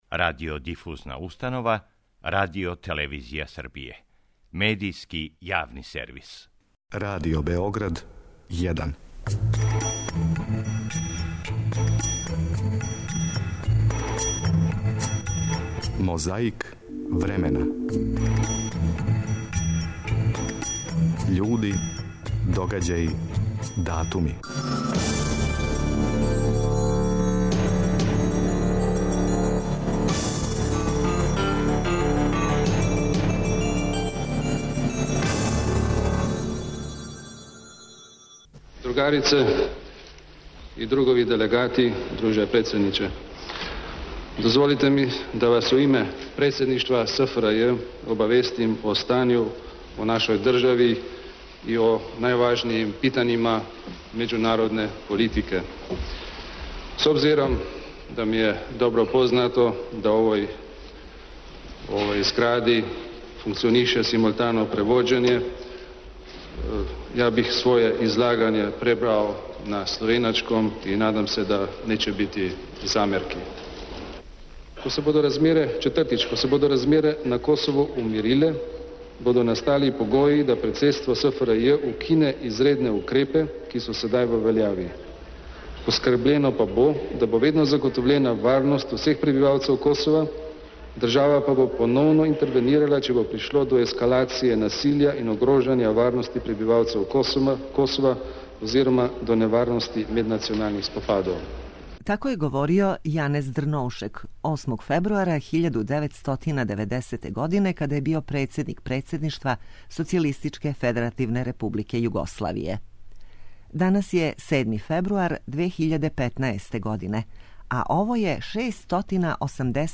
Ми памтимо, а Мозаик времена почињемо подсећањем на двојезично излагање Јанеза Дрновшека, који је тада - 8. фебруара 1990. године - био председник Председништва СФРЈ.